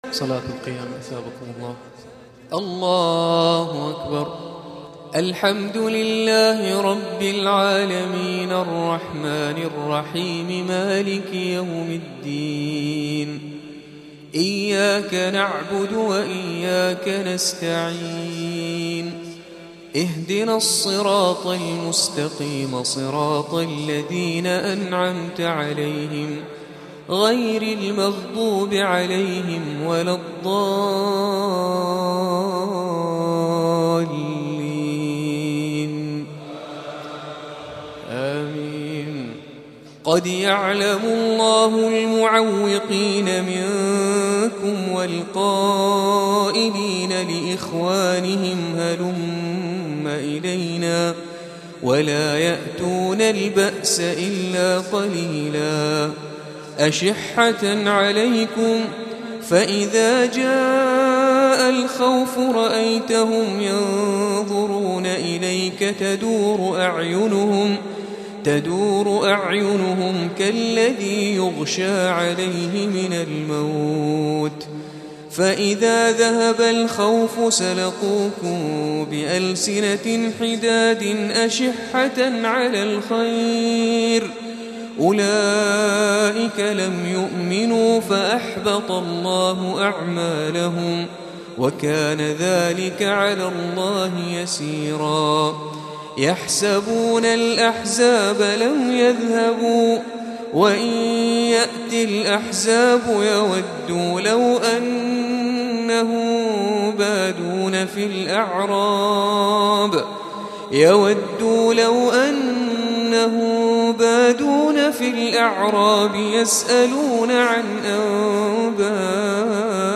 اغاني ليالي رمضانية لعام 1439 هـ , البومات ليالي رمضانية لعام 1439 هـ ,تلاوات من صلاة التراويح والقيام,